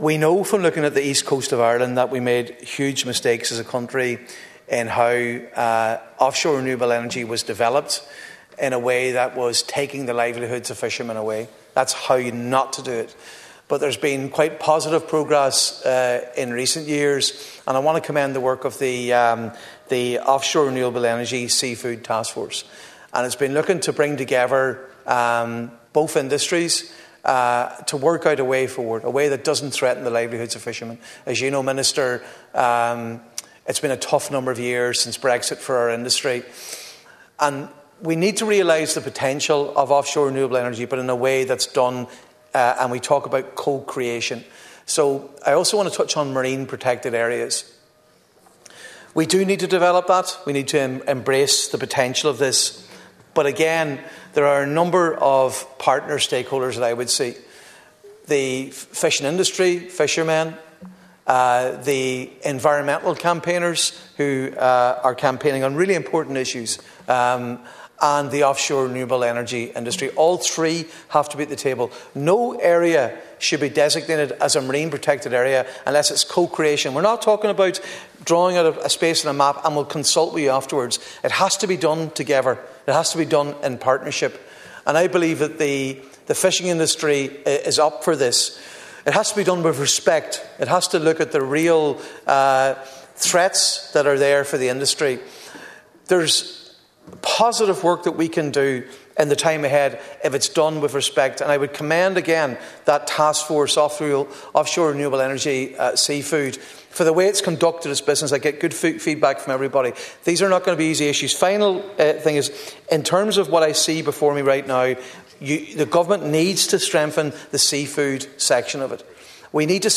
The Dail’s been told the revised National Planning Framework must be strengthened in terms of maximising the potential for offshore energy while respecting the seafood sector and others who make their livelihood on he water.
Deputy Padraig MacLochlainn, the Sinn Fein Marine Spokesperson, said government must ;earn from the mistakes of the past and ensure that there is meaningful engagement right from the start.